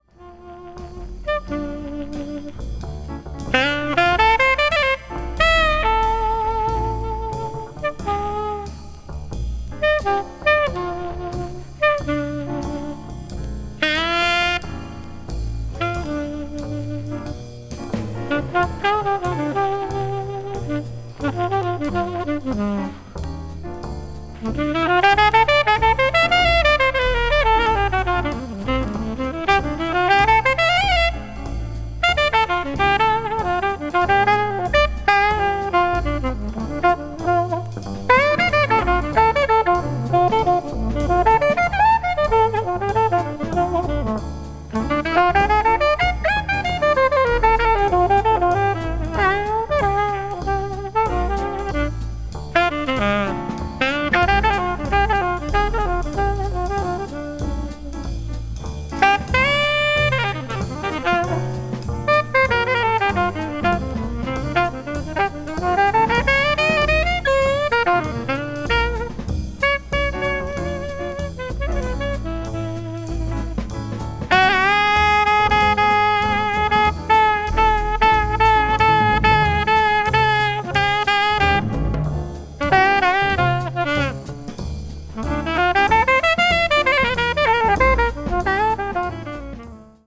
ジャズ・スタンダード・ナンバーをムーディかつブルージーに吹き上げたA1
afro cuban jazz   cool jazz   modern jazz   post bop